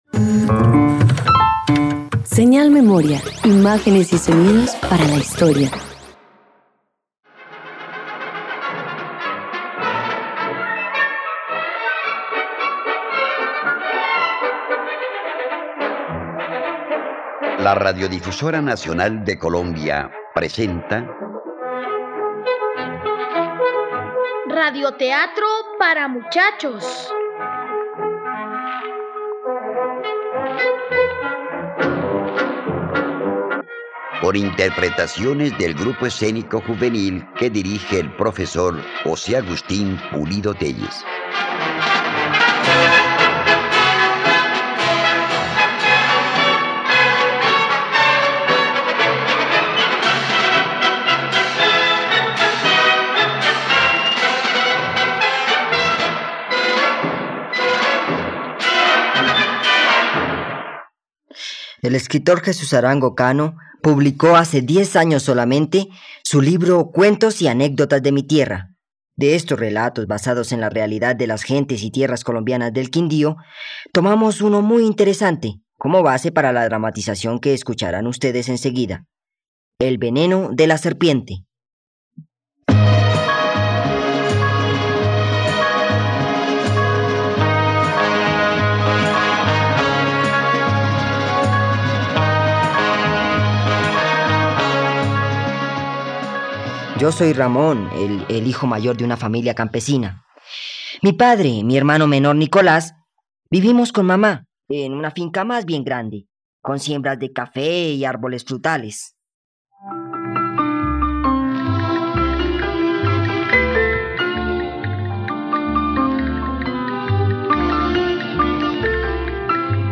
El veneno de la serpiente - Radioteatro dominical | RTVCPlay